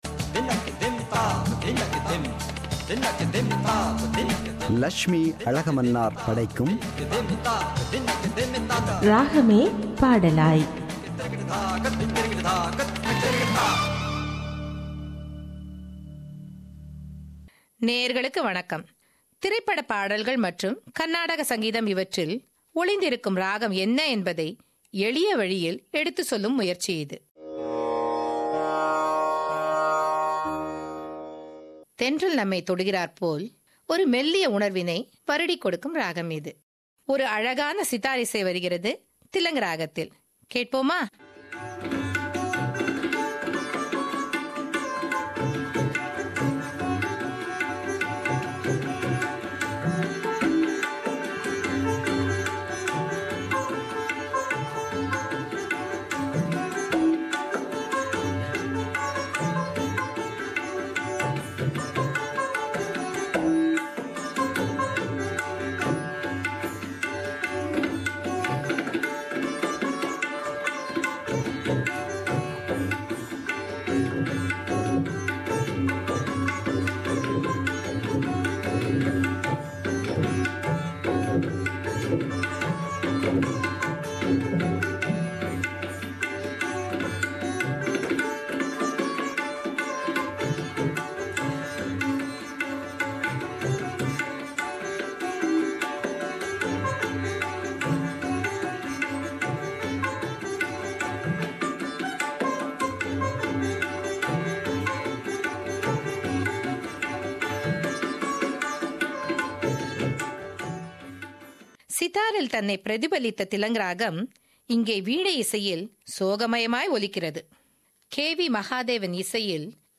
இன்றைய நிகழ்ச்சியில் திலங் ராகம் குறித்து அவர் தொடர்ந்து இந்த வாரமும் விளக்குகிறார். ராகம் எப்படி பாடல் வடிவம் பெறுகிறது என்பதை கர்நாடக இசை மற்றும் திரைப்பட இசை ஒலிக்கீற்றுகளைக் கலந்து இந்நிகழ்ச்சியை முன்வைக்கிறார்